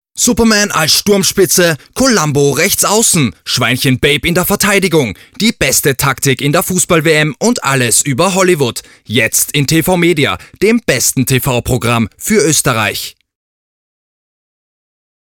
Sympathisches, mittleres/dunkles Timbre, vielseitig einsetzbar.
Sprechprobe: Industrie (Muttersprache):